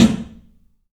PABSNARE101L.wav